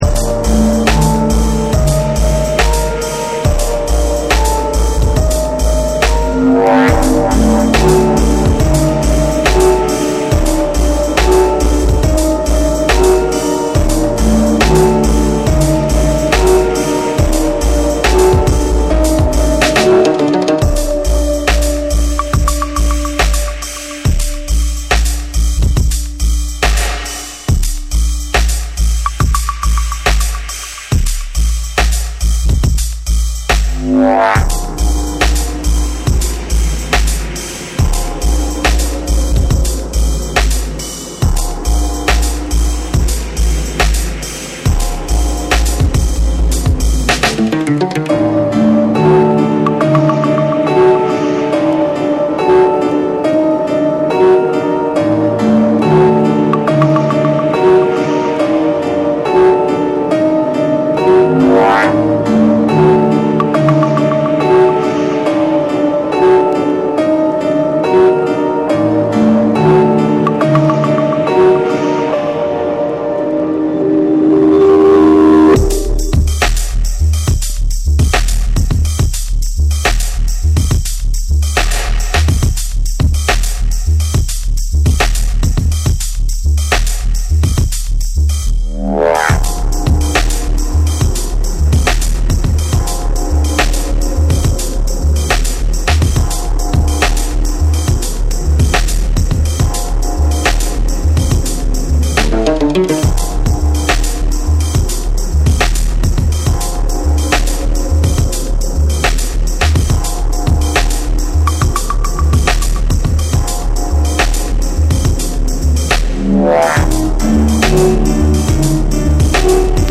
ダブステップ〜サウンドシステム・カルチャーを軸に、重厚なベースと空間処理を駆使した深遠なサウンドスケープを展開。
スモーキーで催眠的な
BREAKBEATS / REGGAE & DUB